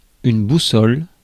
Ääntäminen
Synonyymit compas Ääntäminen France: IPA: /bu.sɔl/ Haettu sana löytyi näillä lähdekielillä: ranska Käännös Substantiivit 1. компас {m} (kompas) Suku: f .